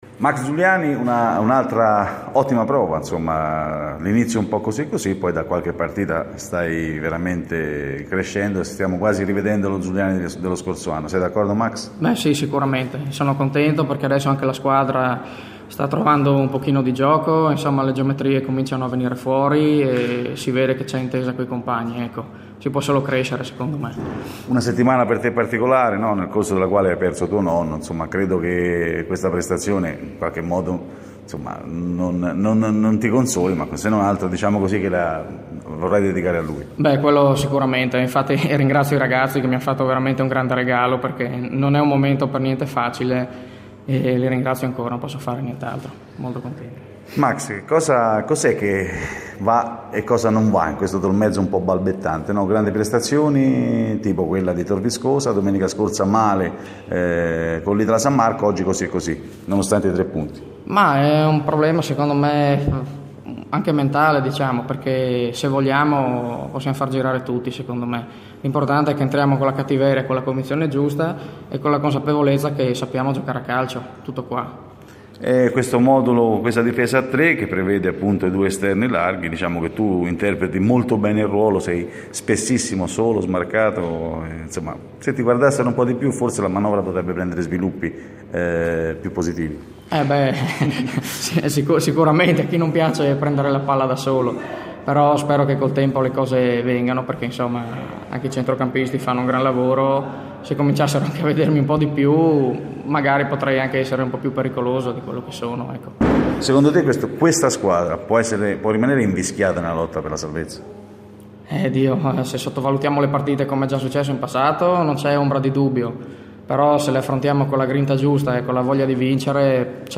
audiointerviste